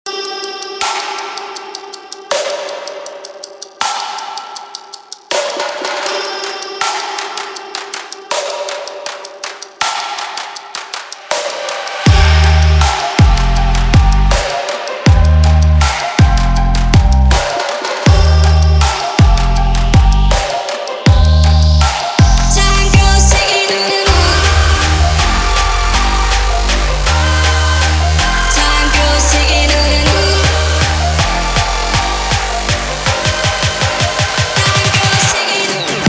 • Качество: 320, Stereo
Дабстеп
Стиль: Dupstep